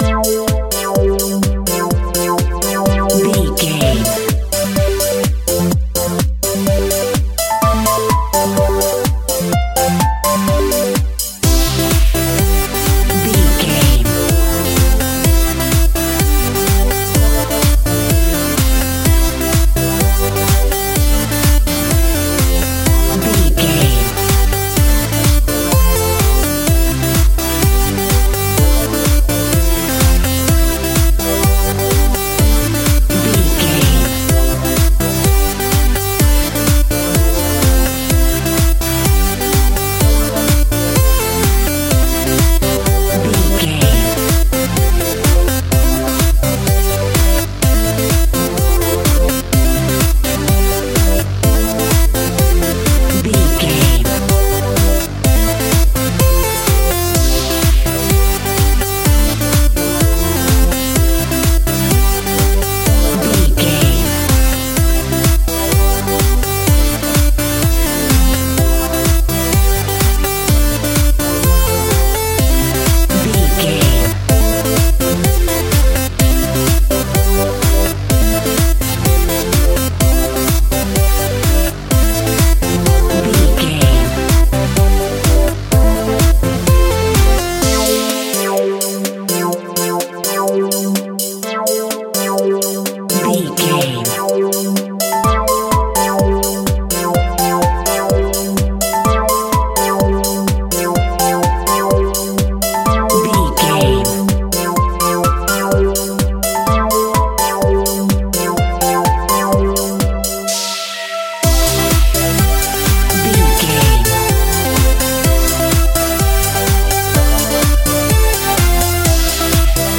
Ionian/Major
futuristic
frantic
drum machine
synthesiser
electronic
electronica
synth drums
synth leads
techno music
synth bass
synth pad
robotic